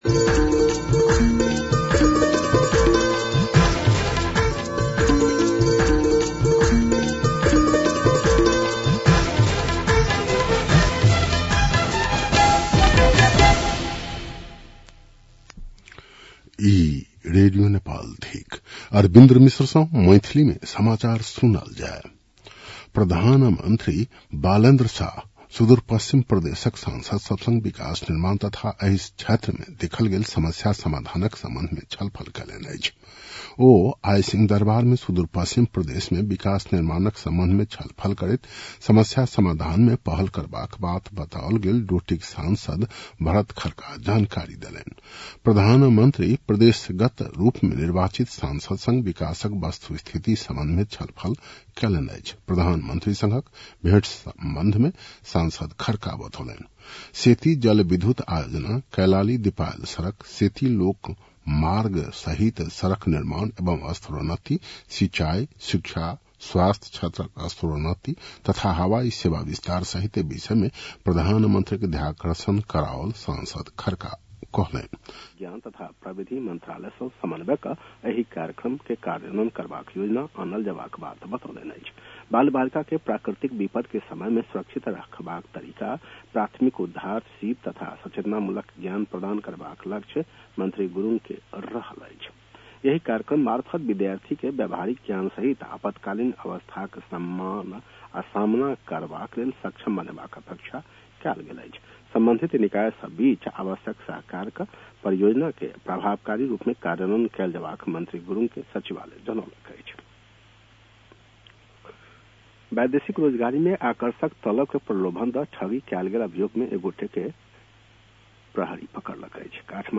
मैथिली भाषामा समाचार : १७ चैत , २०८२